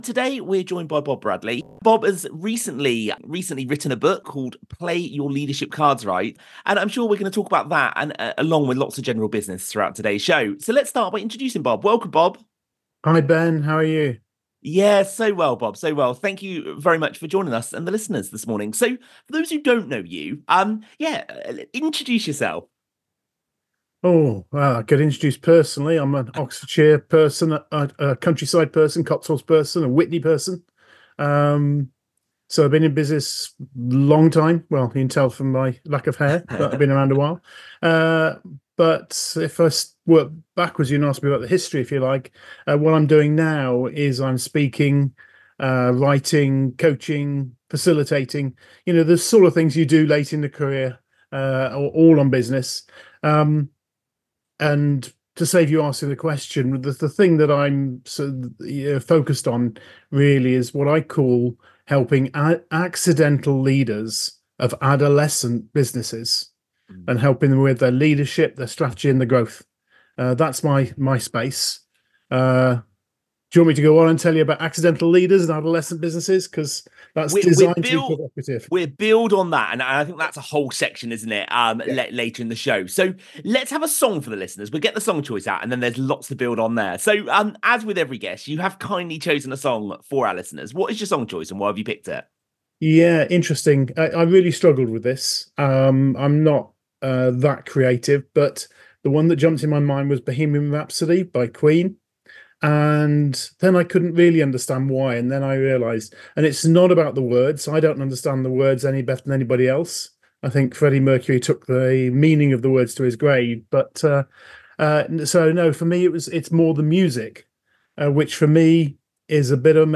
Radio Interview: Play Your Leadership Cards Right – 20 Practical Leadership Tips